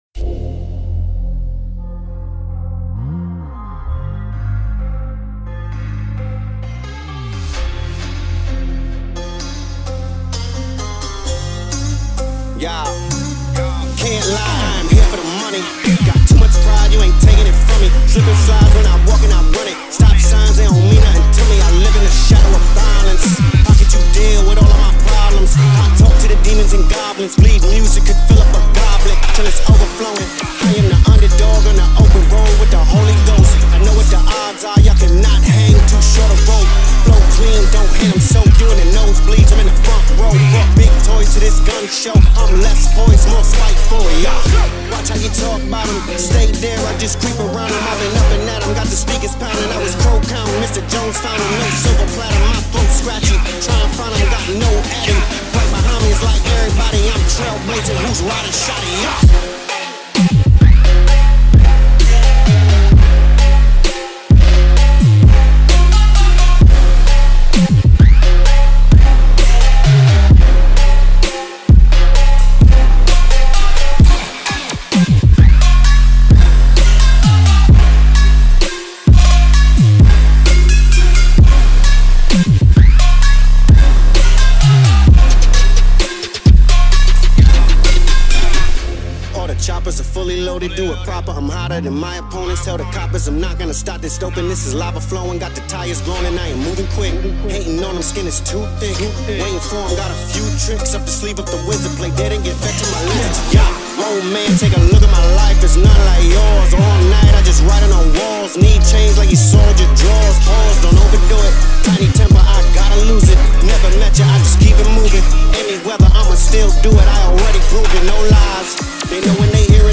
(bass Boosted)
Data: 26.09.2024  Bass Music D Hits: 0